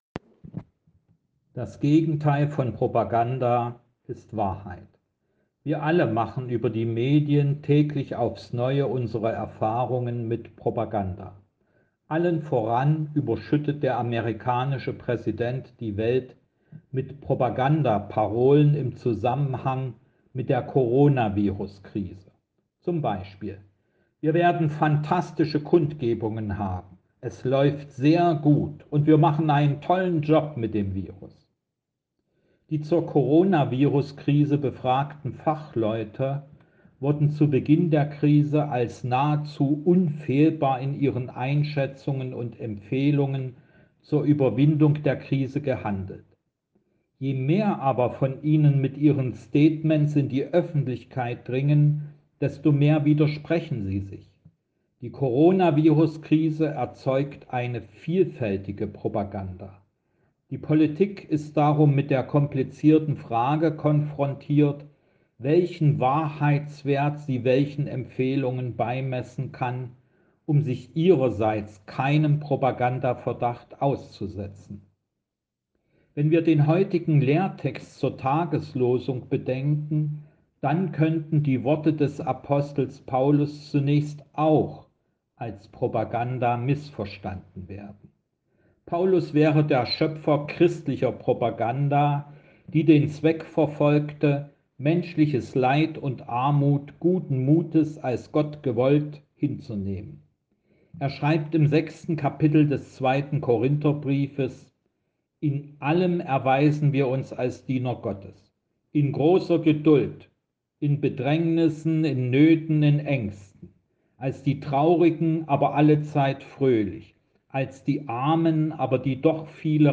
Die Andacht zum Hören